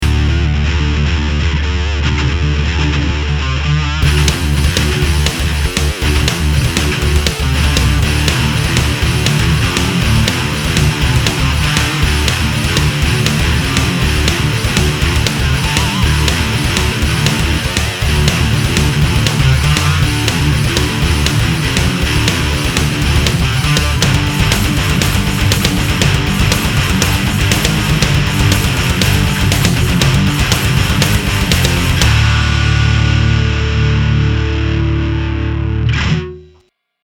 Gitarové Kombo Trubica Zosilnovača
Metropolitan20Modern20Metal.mp3